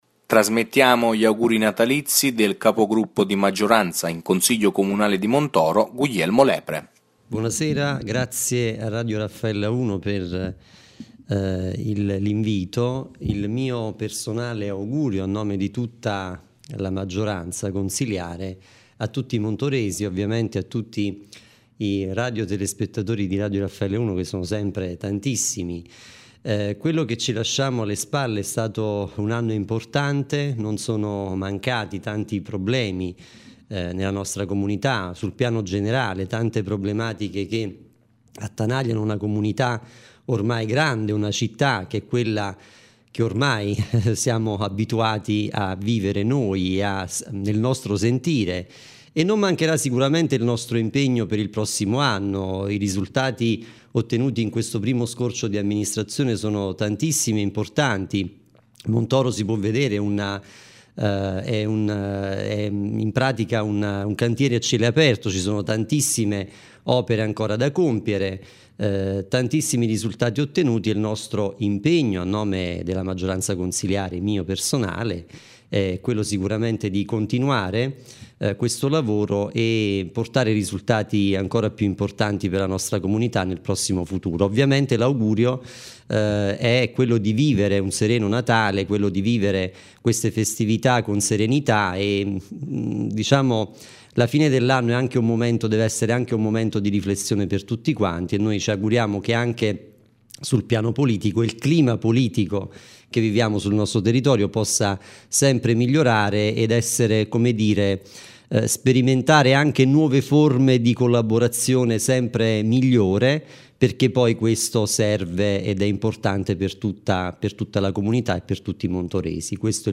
Il Sindaco di Montoro Bianchino, il Capogruppo di Maggioranza Lepre ed il Sindaco di Solofra Vignola, attraverso i nostri microfoni hanno augurato buone feste ai cittadini delle rispettive comunità.
Gli auguri del Capogruppo Lepre:
Auguri-Natalizi-del-Consigliere-Comunale-di-Montoro-Guglielmo-Lepre-2015.mp3